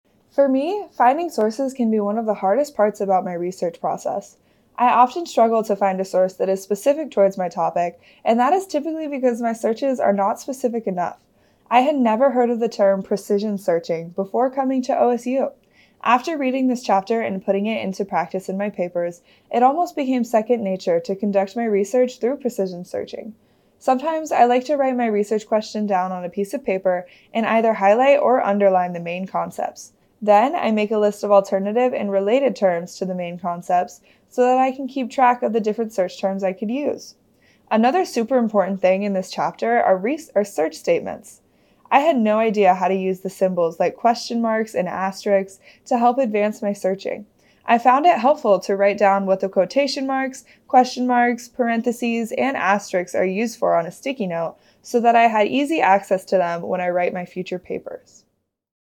Student Voices